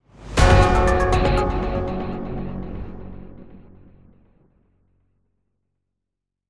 Category 🎮 Gaming
fanfare find-item get-item happy intense item-get level-up mission-complete sound effect free sound royalty free Gaming